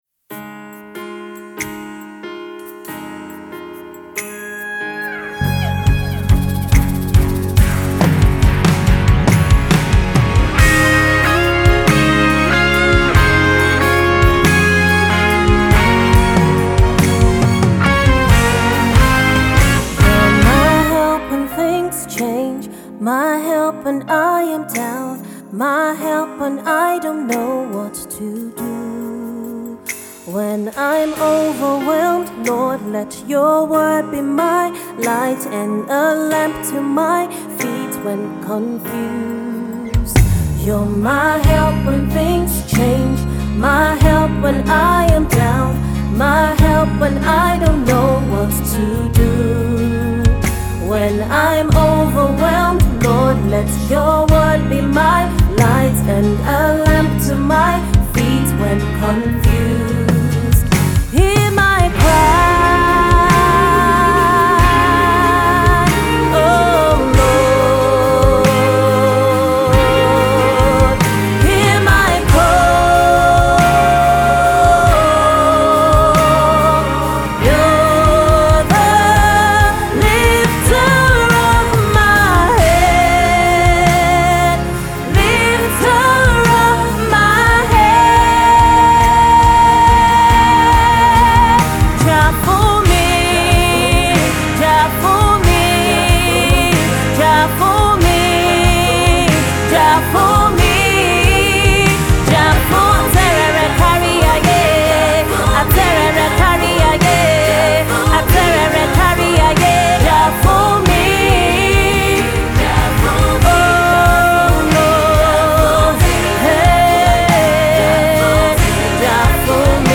christian singer/songwriter